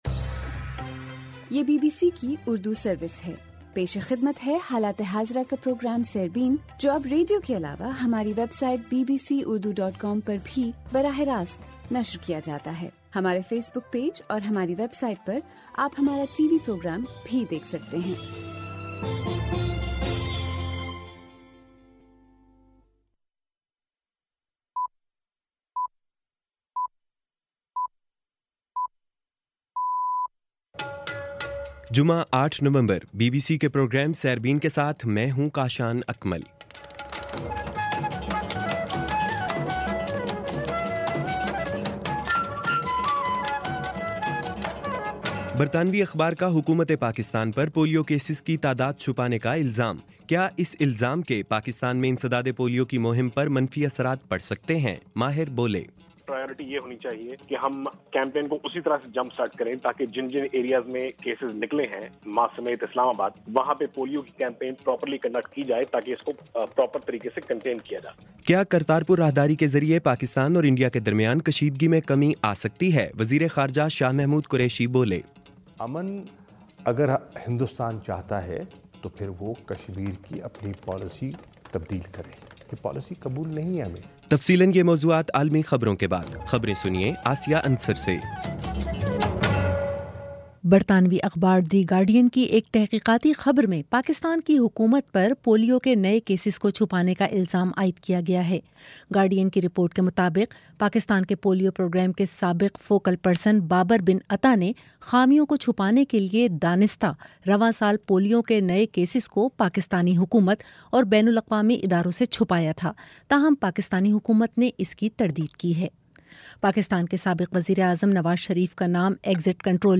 جمعہ 08 نومبر کا سیربین ریڈیو پروگرام